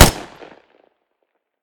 smg-shot-05.ogg